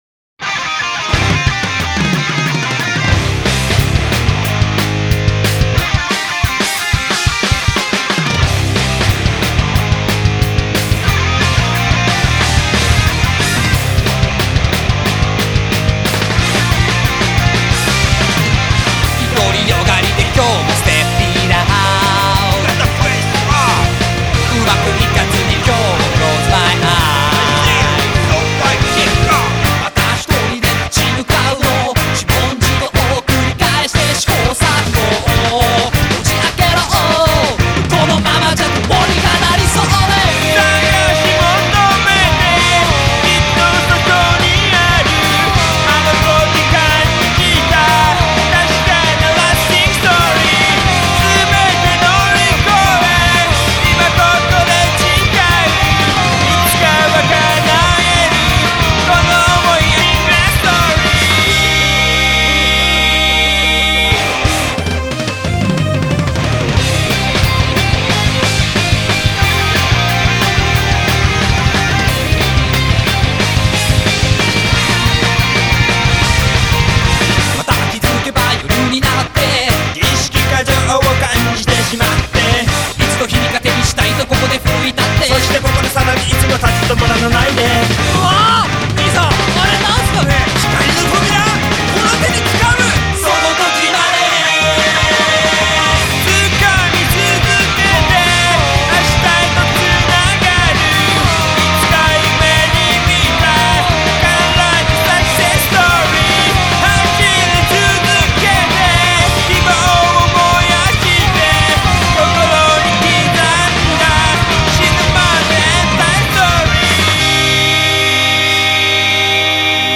BPM181
Audio QualityPerfect (High Quality)
[BALLS OUT ROCK]